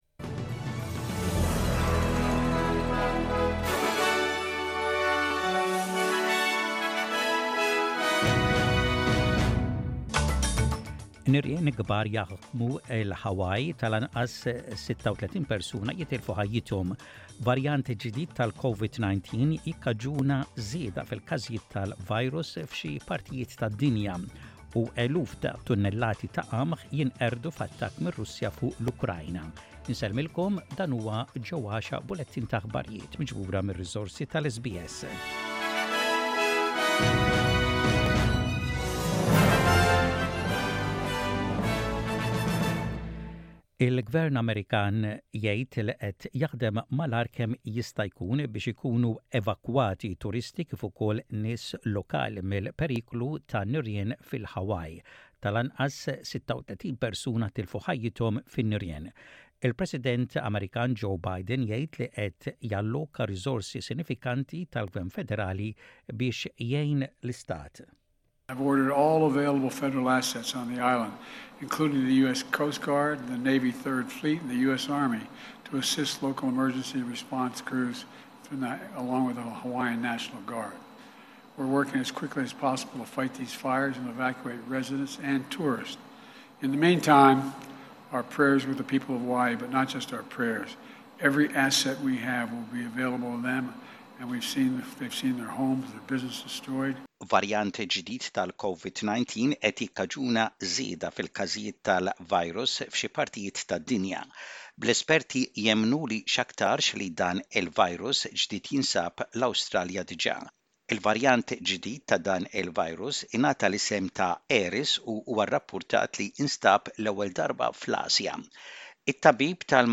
SBS Radio | Maltese News: 11.08.23